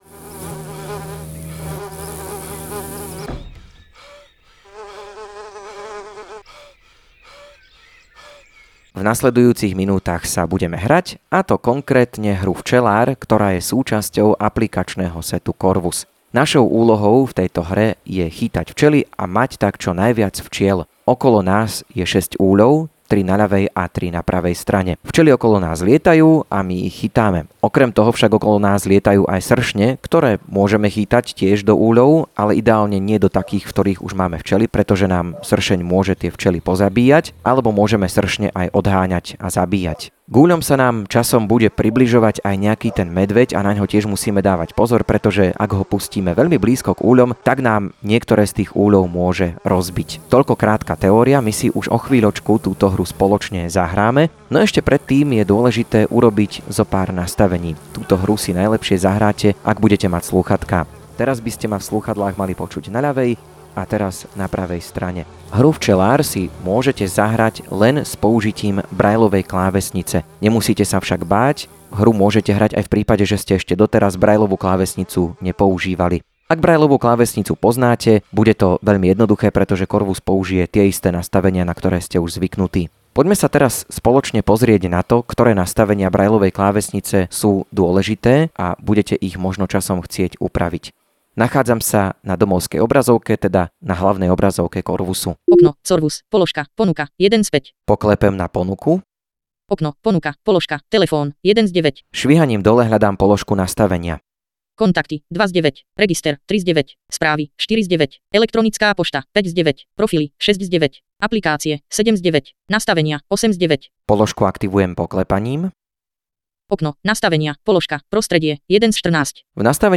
Ako samotná hra funguje aj s komentárom od tvorcov si môžete vypočuť na tomto audio odkaze.